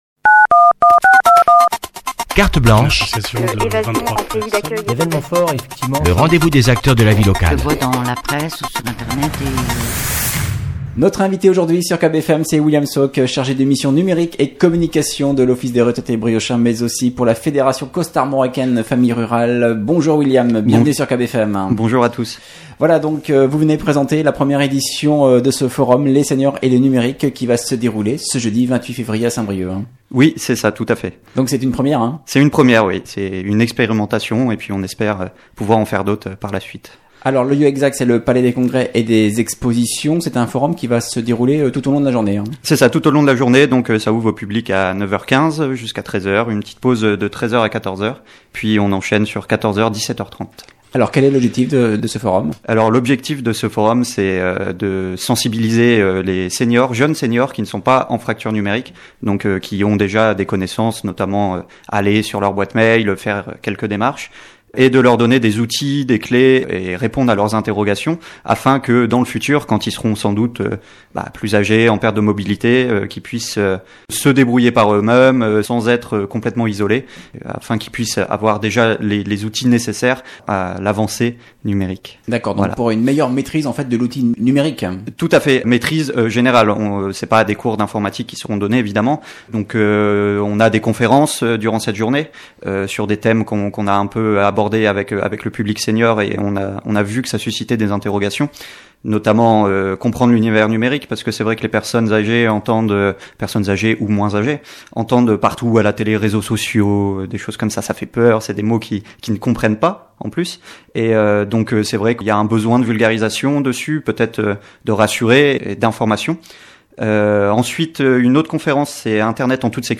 Invité de la rédaction ce vendredi